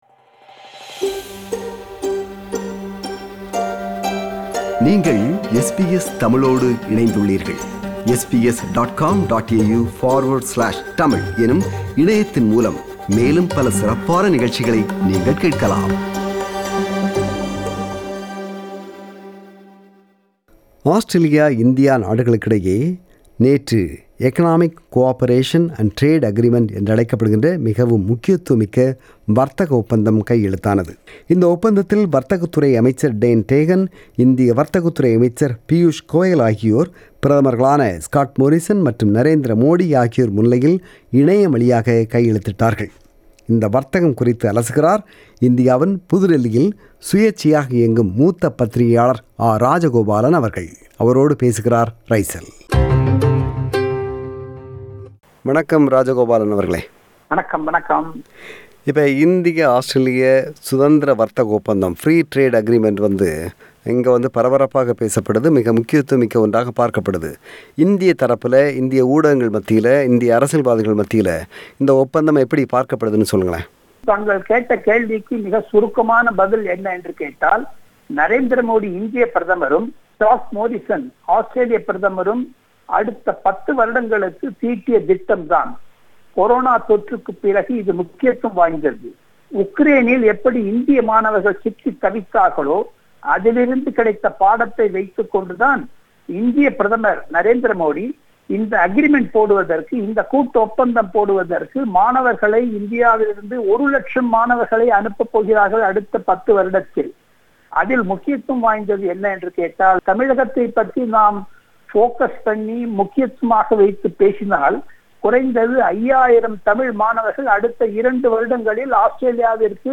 a leading independent journalist in New Delhi, explains the salient features of the trade deal and analyses its political significance.